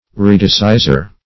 Redisseizor \Re`dis*sei"zor\ (-z?r), n. (Law) One who redisseizes.